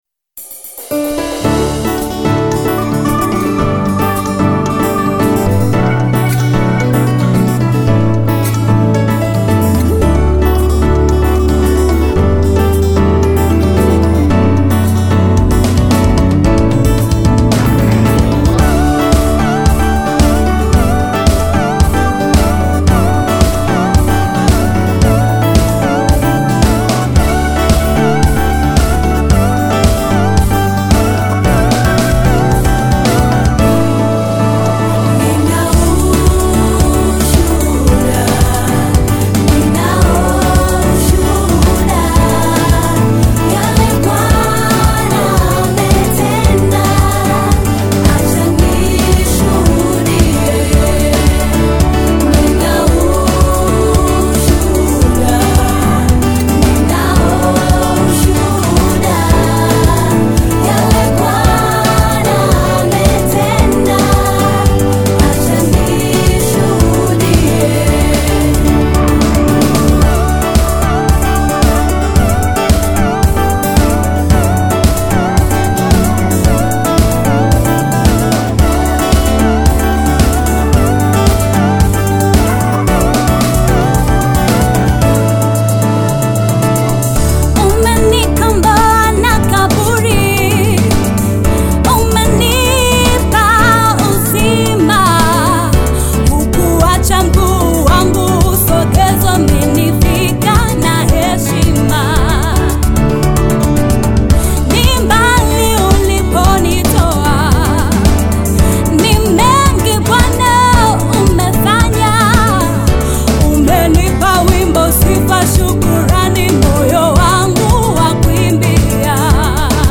NEW GOSPEL AUDIO SONG